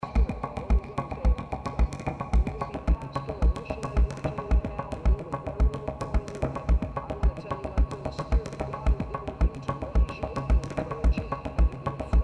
performed live